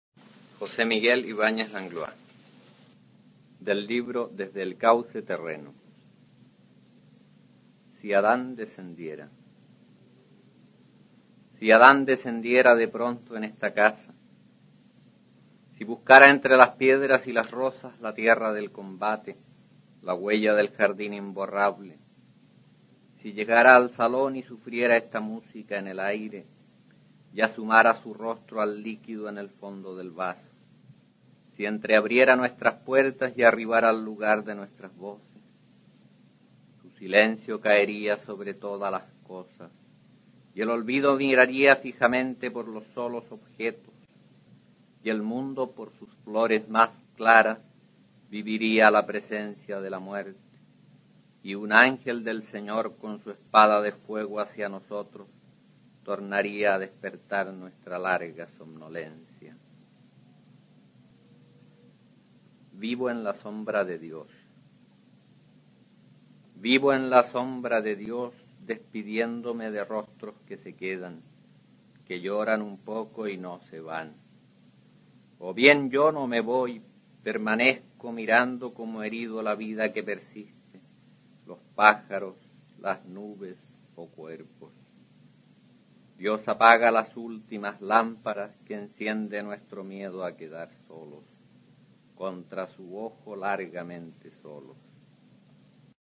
Aquí se puede oír al autor chileno José Miguel Ibáñez Langlois recitando sus poemas Si Adán descendiera y Vivo en la sombra de Dios, del libro "Desde el cauce terreno" (1956).